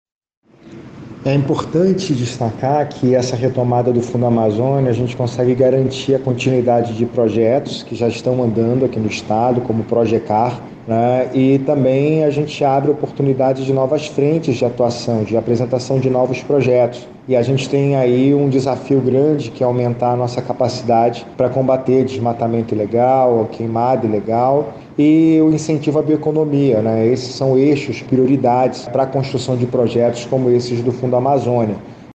O secretário da Sema, Eduardo Taveira, relata a importância da retomada do Fundo Amazônia por meio do BNDES, para dar continuidade do ProjeCAR e, futuramente, financiar novos projetos ambientais, principalmente, no interior do Amazonas.
Sonora-Eduardo-Taveira-secretario-da-Sema.mp3